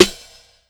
Crash Snare.wav